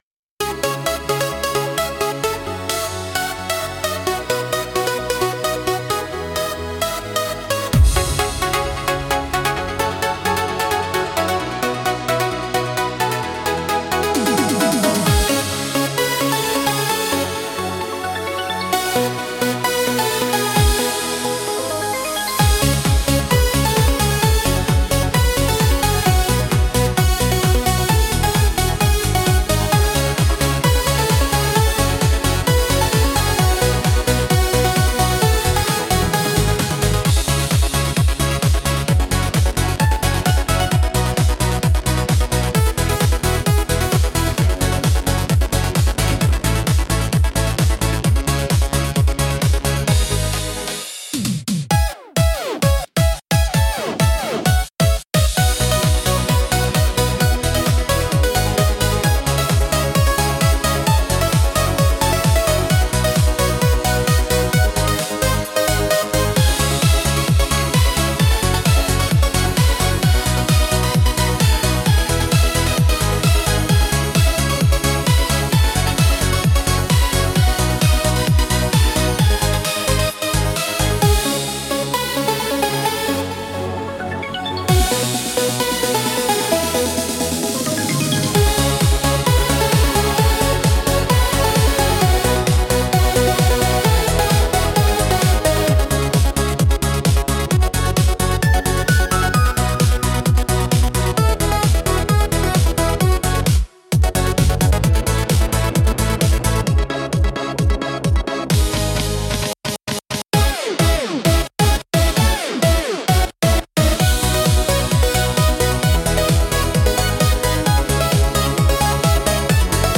Instrumental - Black Leather Circuit 2.29